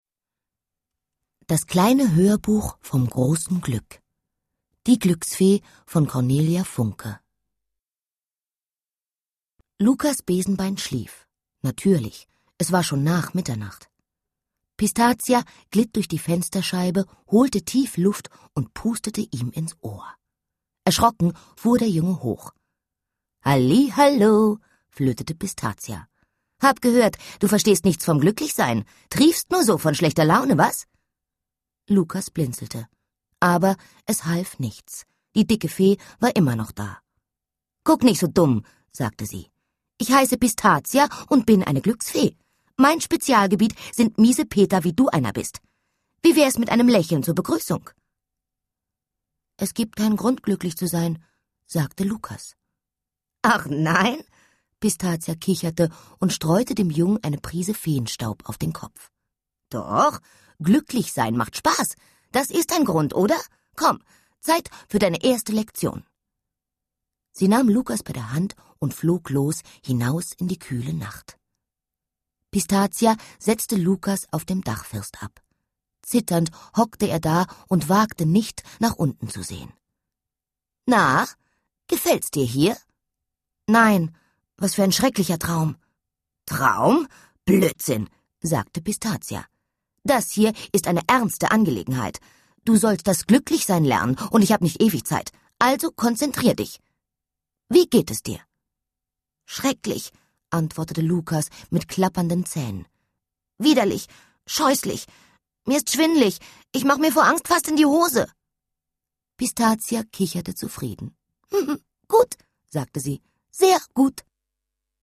Das kleine Hörbuch vom großen Glück (DAISY Edition) Mit den Geschichten "Die Glücksfee" und "Wo das Glück wächst" Cornelia Funke (Autor) Anna Thalbach (Sprecher) Audio-CD 2009 | 1.